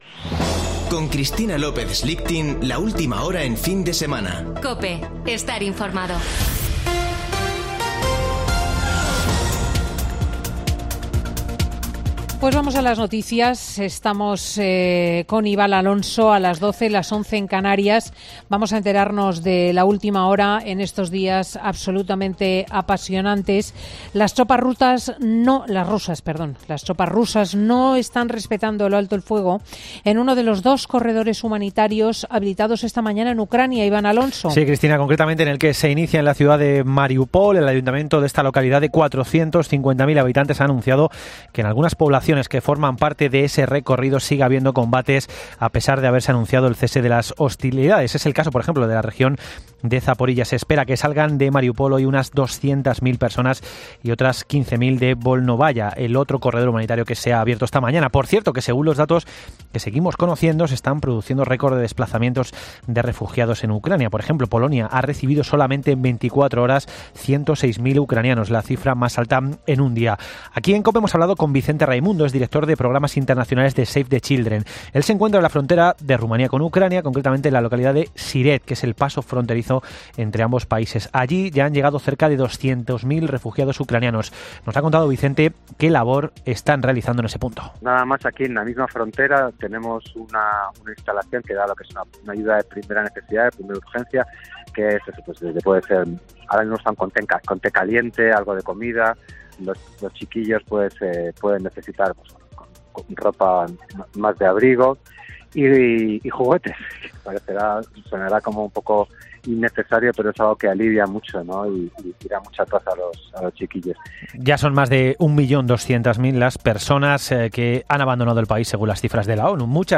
Boletín de noticias COPE del 5 de marzo de 2022 a las 12.00 horas